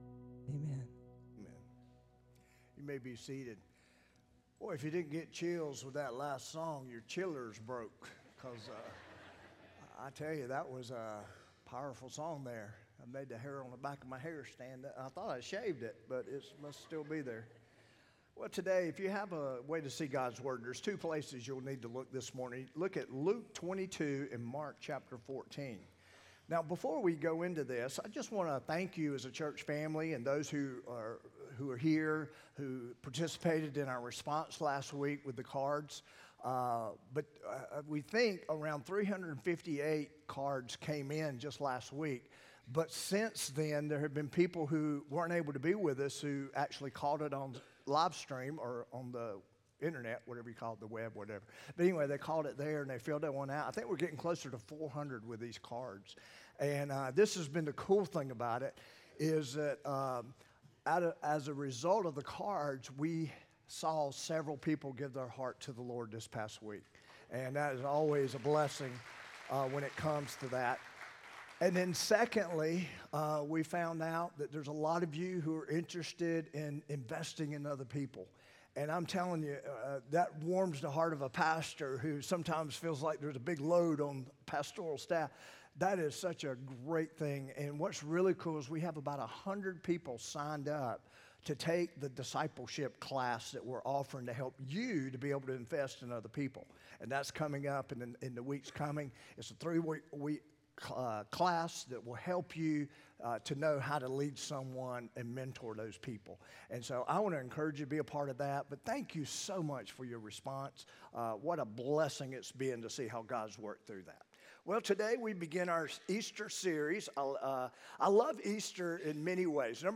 4-6-25-sermon-audio.m4a